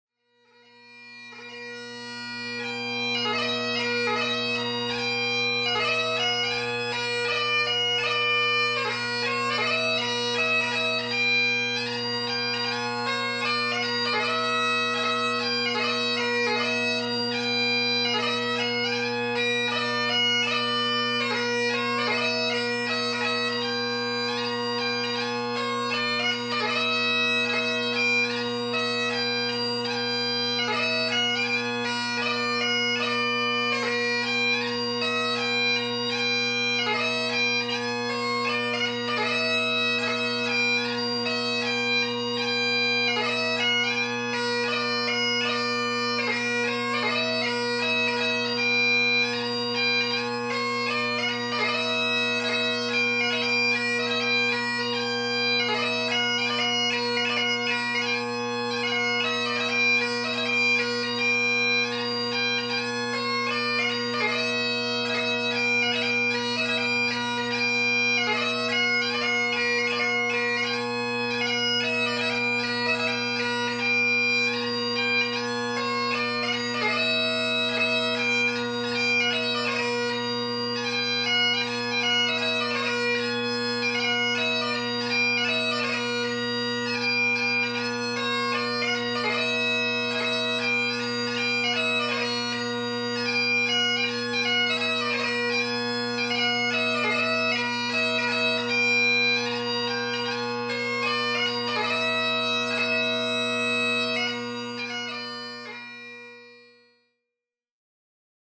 Bells of Malta, a three part tune by P/M McLellan.